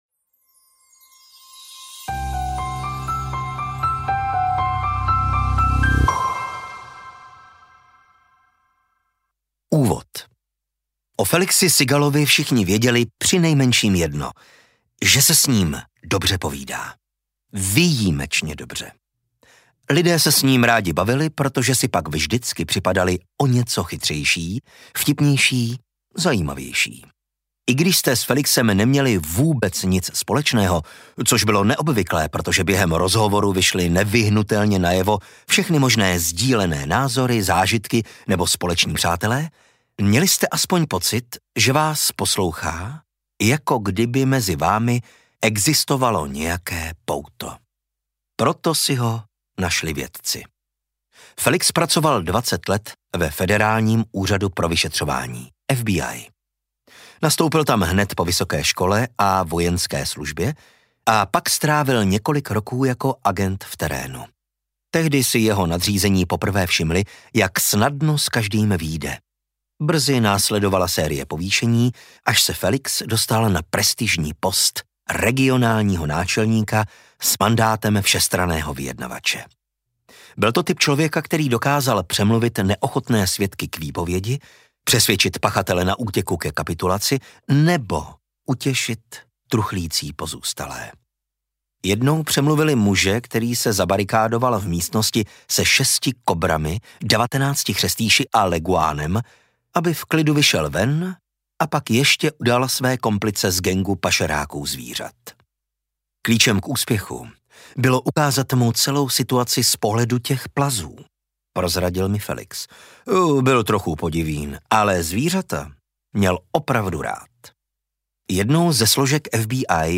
Superkomunikátoři audiokniha
Ukázka z knihy